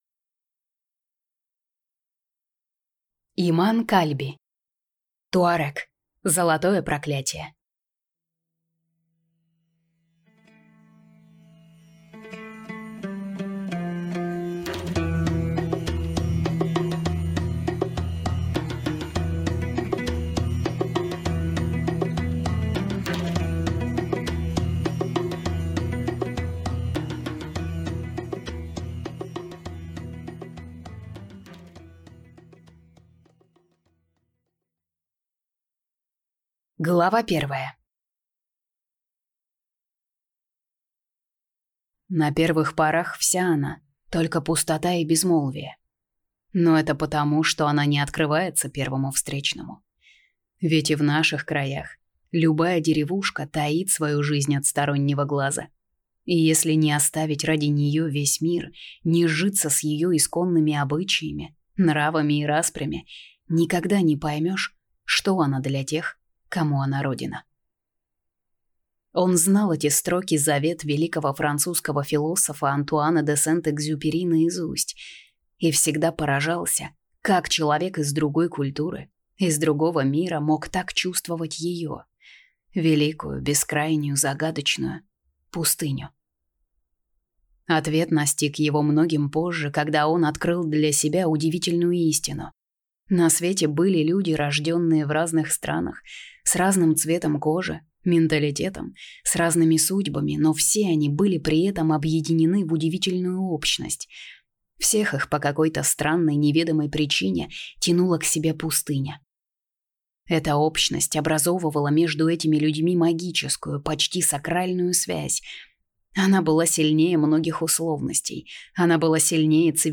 Аудиокнига Туарег. Золотое проклятие | Библиотека аудиокниг